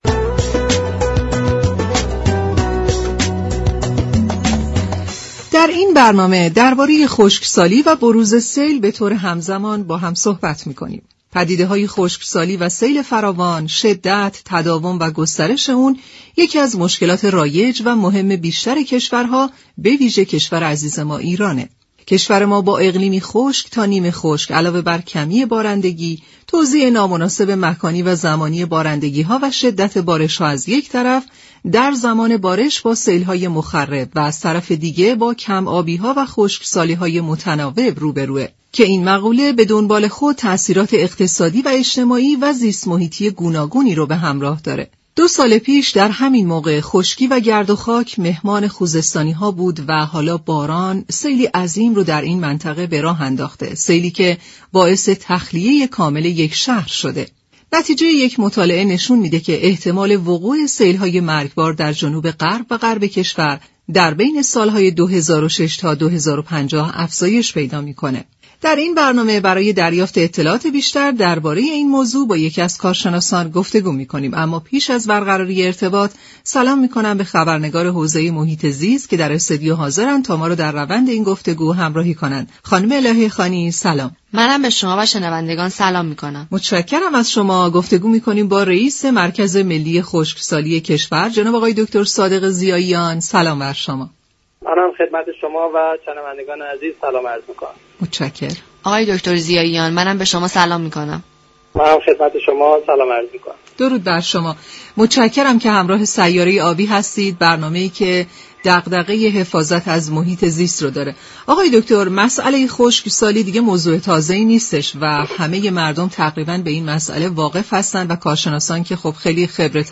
رییس مركز خشكسالی كشور در گفت و گو با رادیو ایران گفت: بخشی از معضلات به وجود آمده جهانی است و رفع آن ها همكاری همه كشورها را می طلبد.